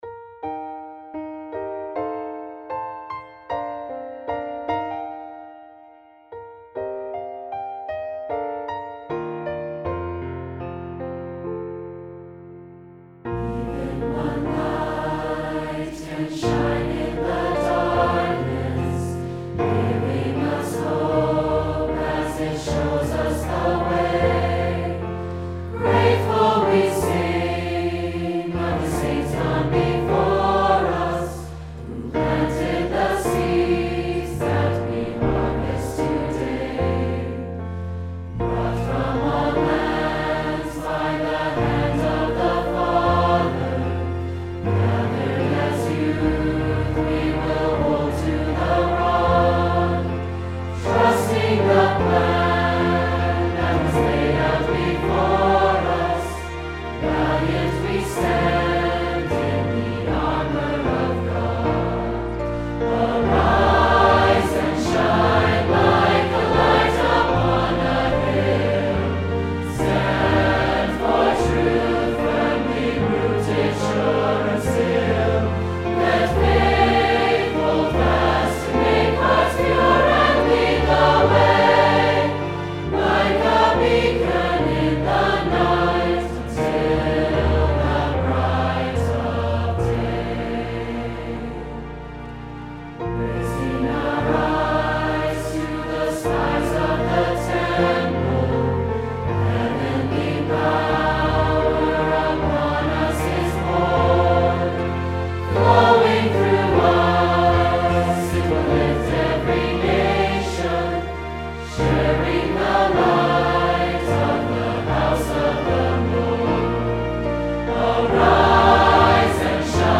One-part, two-part, or SATB and piano.
Choir version